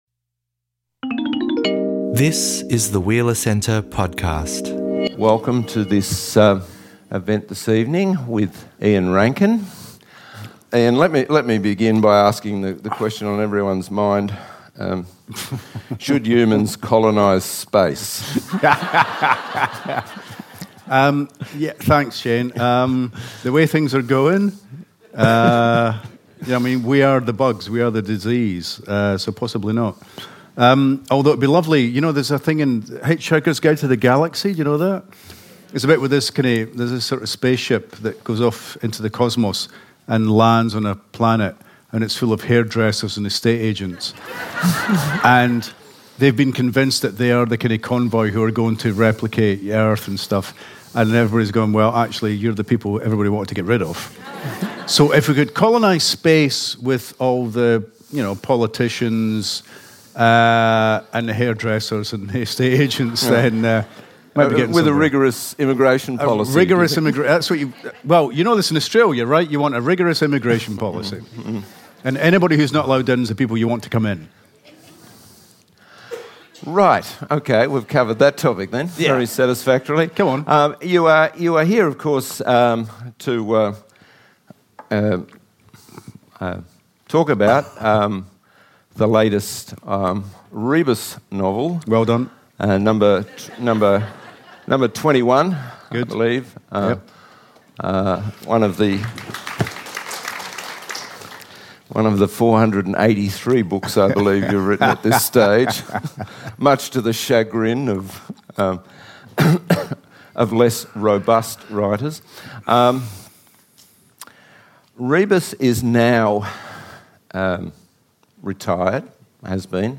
At Melbourne City Conference Centre, the king of tartan noir talks mystery, human nature and the dark side of cities with Shane Maloney.